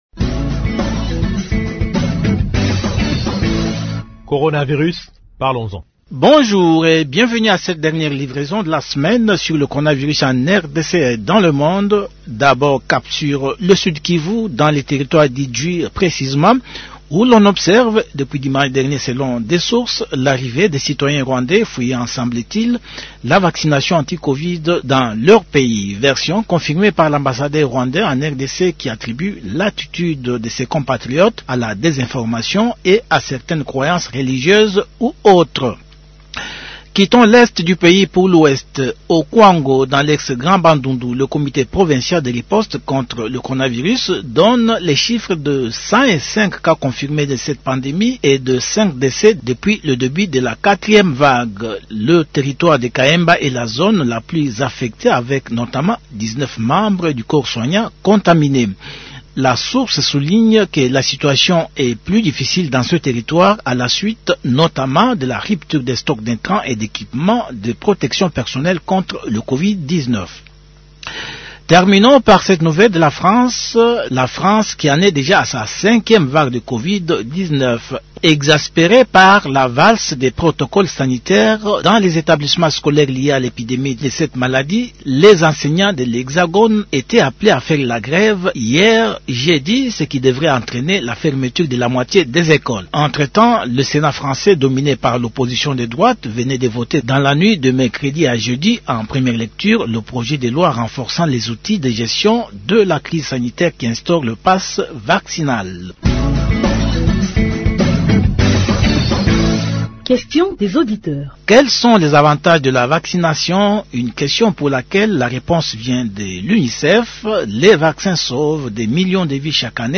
Actualité
Promo